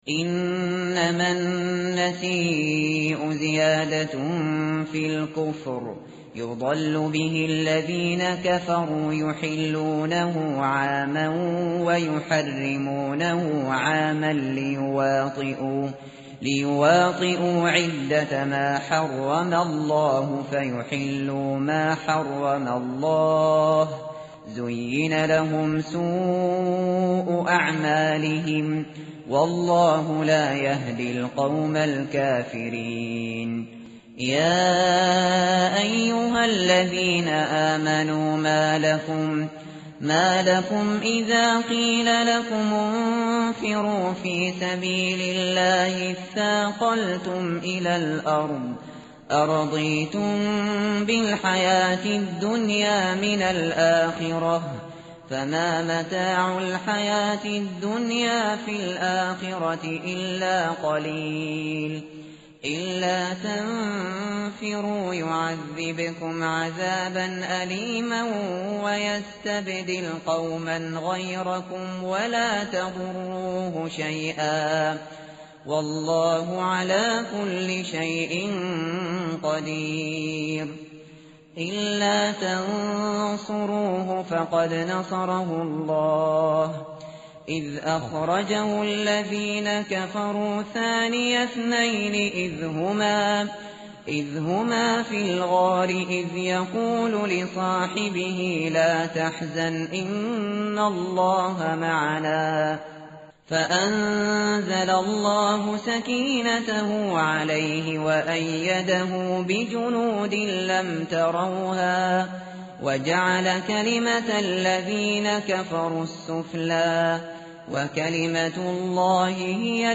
متن قرآن همراه باتلاوت قرآن و ترجمه
tartil_shateri_page_193.mp3